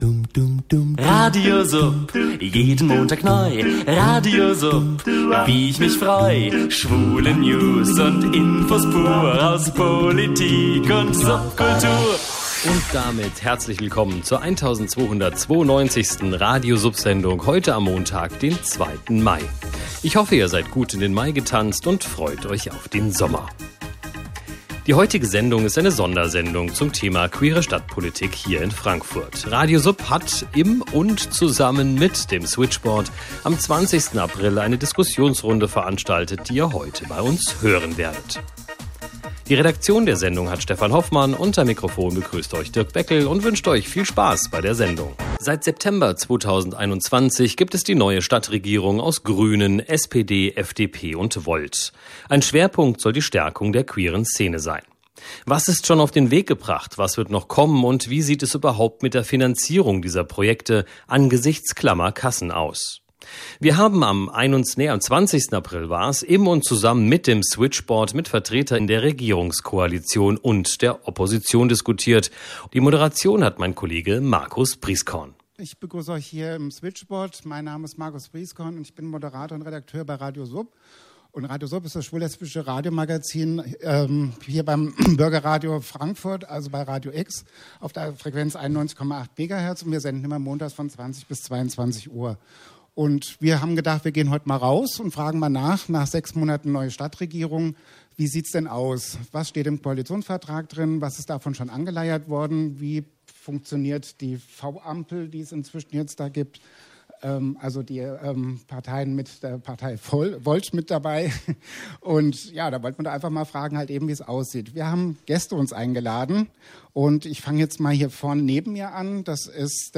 1292_diskussion.mp3